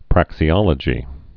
(prăksē-ŏlə-jē)